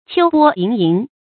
秋波盈盈 注音： ㄑㄧㄡ ㄅㄛ ㄧㄥˊ ㄧㄥˊ 讀音讀法： 意思解釋： 形容眼神飽含感情。